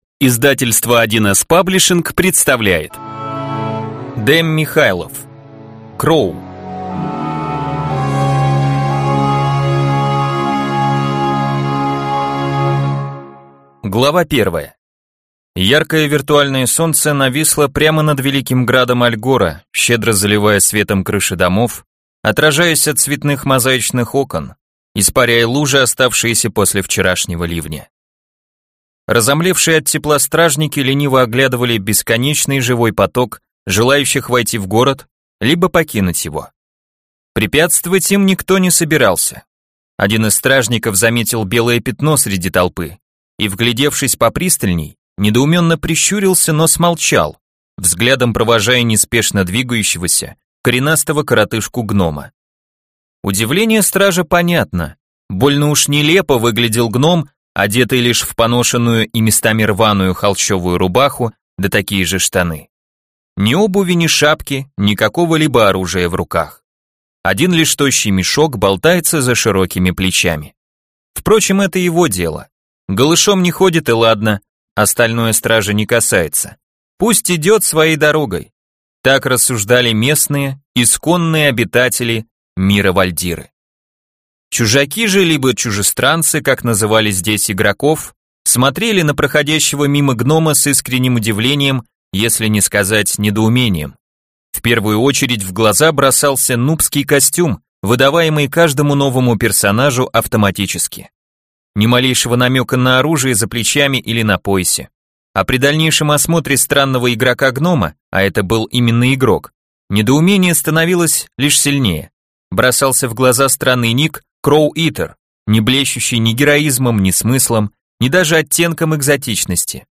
Аудиокнига Кроу - купить, скачать и слушать онлайн | КнигоПоиск